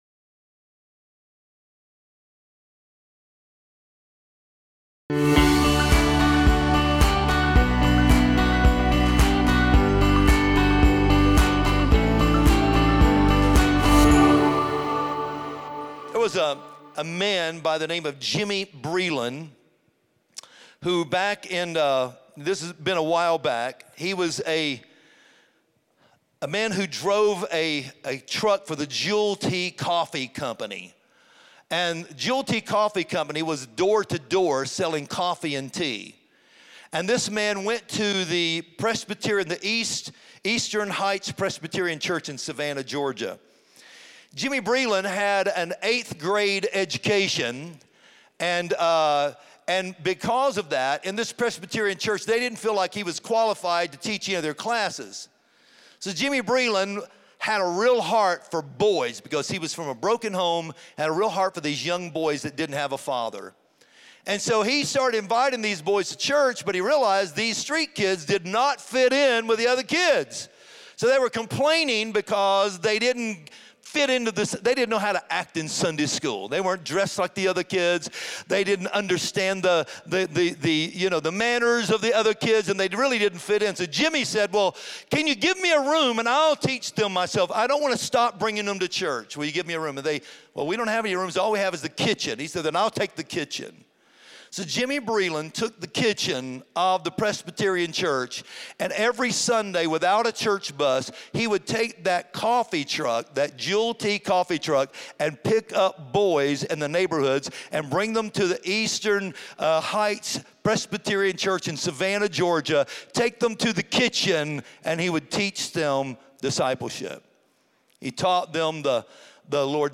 Join us this week for the sermon “Make a Difference”